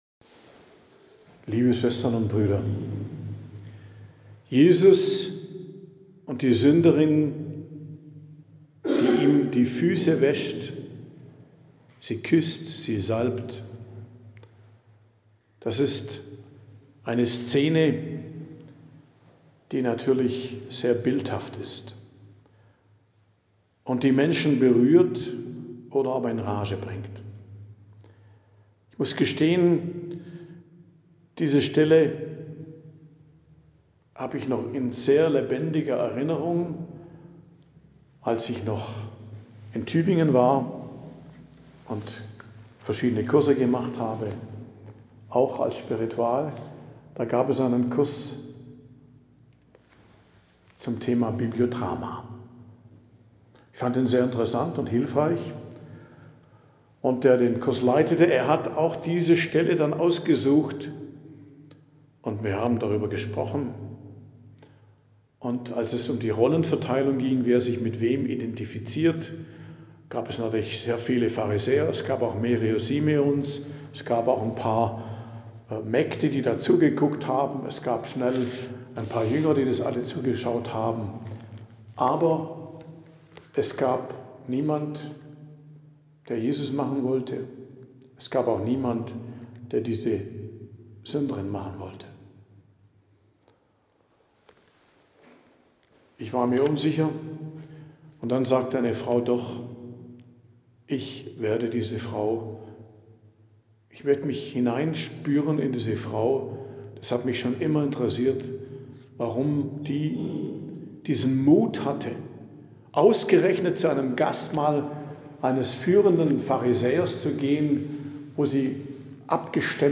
Predigt am Donnerstag der 24. Woche i.J., 18.09.2025 ~ Geistliches Zentrum Kloster Heiligkreuztal Podcast